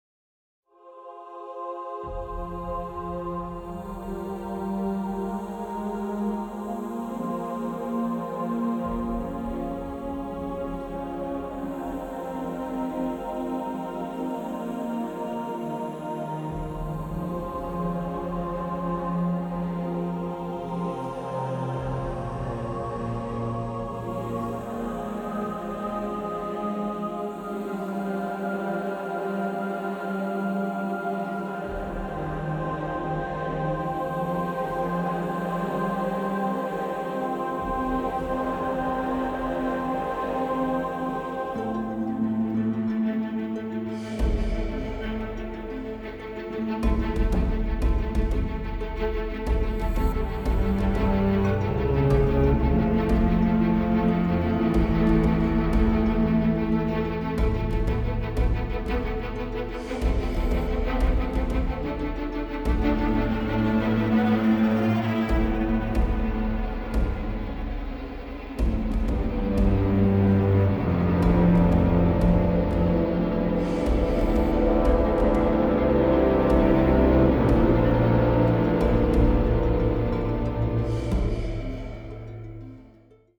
a rousing score